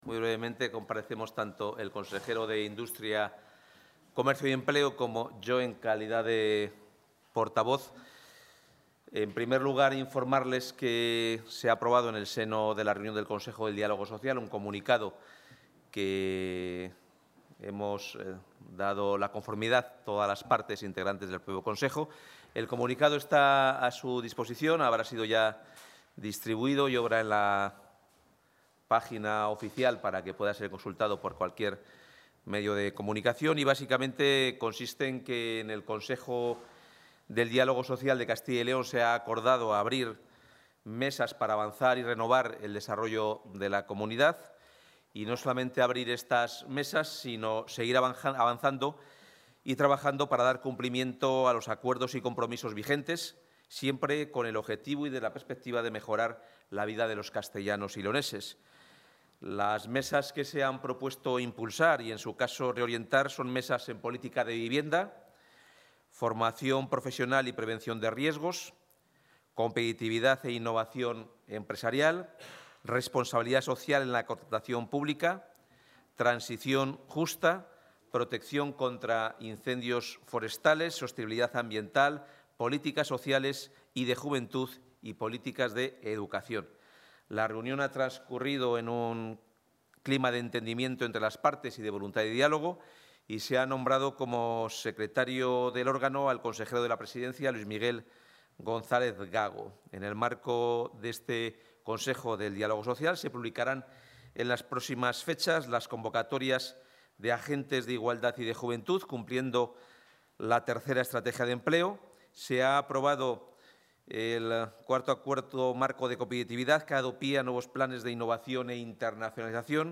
Intervención del portavoz de la Junta.